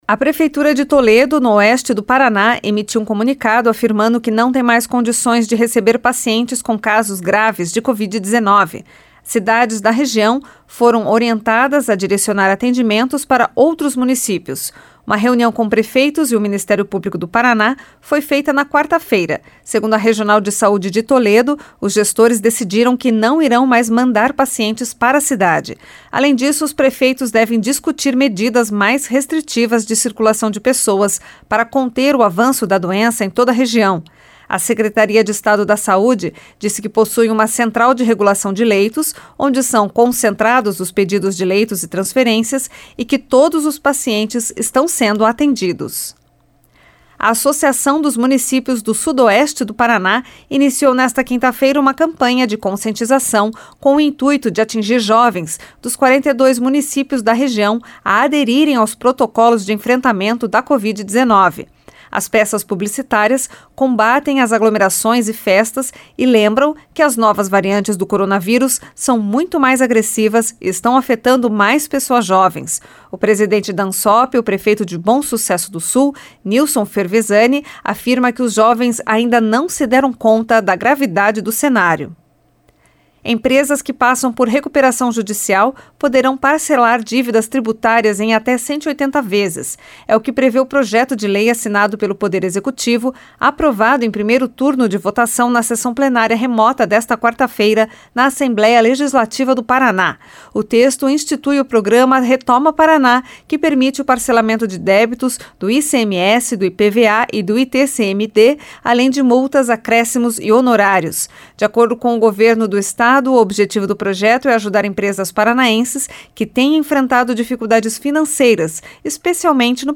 Giro de Notícias Manhã SEM TRILHA